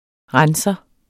Udtale [ ˈʁansʌ ]